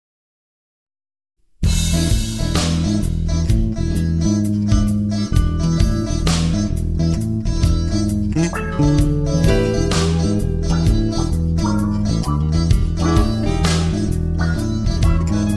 Midi Sequencing